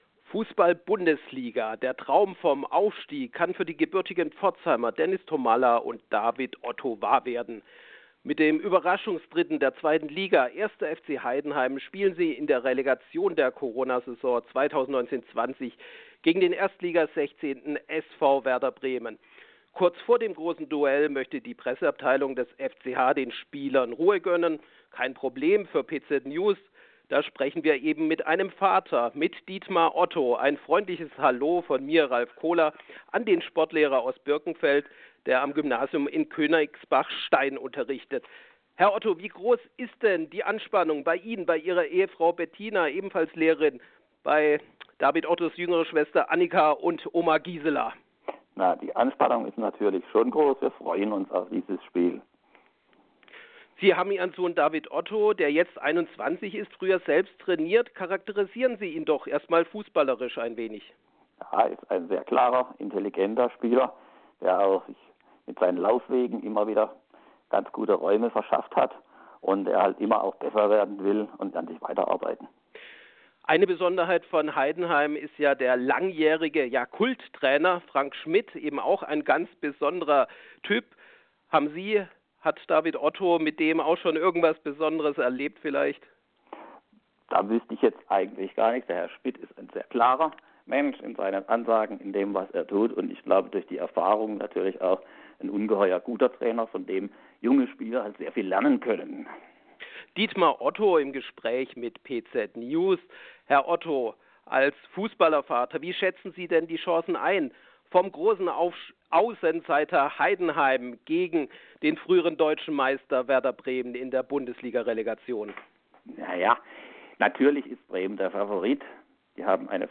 Audio-Interview